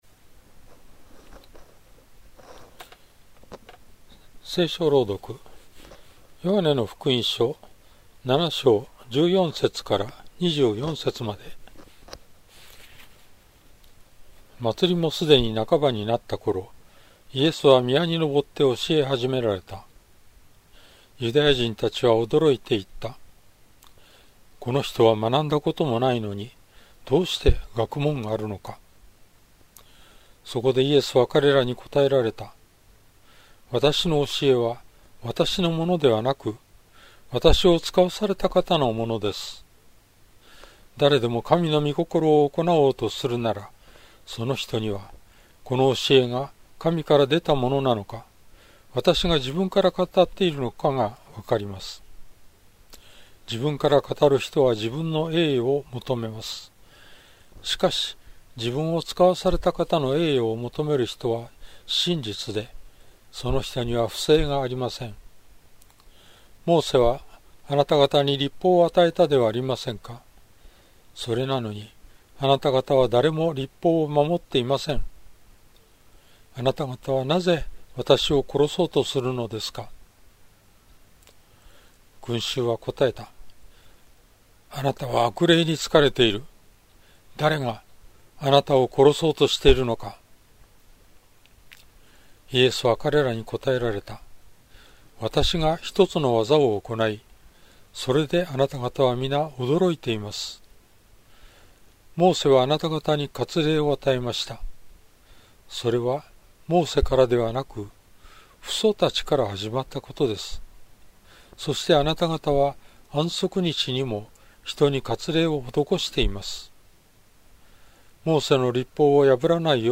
BibleReading_J7.14-24.mp3